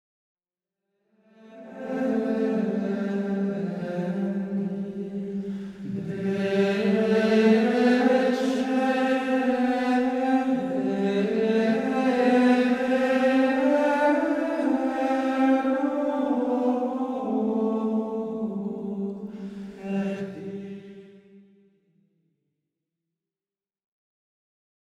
Un choeur très étoffé. Une belle amplitude vocale.
Certains regretteront le côté un peu désincarné des voix, accompagné d'une recherche esthétique un peu ethérée.
Cette atmosphère touche un certain public.